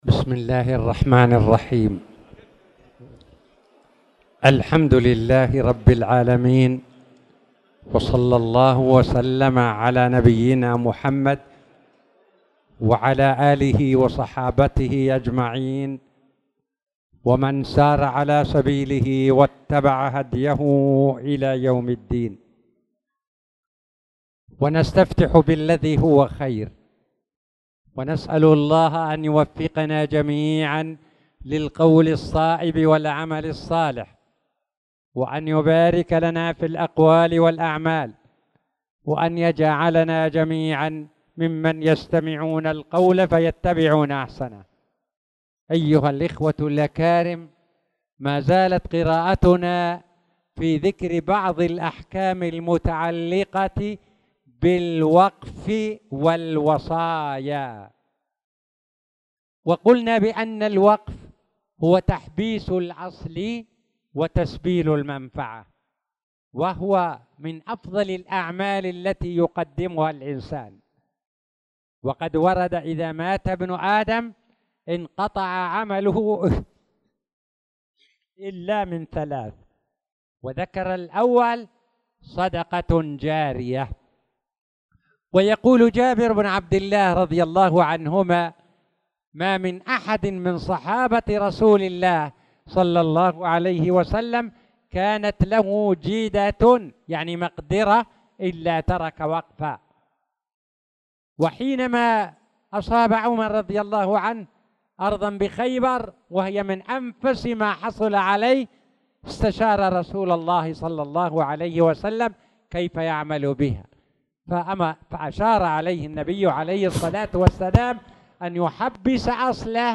تاريخ النشر ١٧ شعبان ١٤٣٧ هـ المكان: المسجد الحرام الشيخ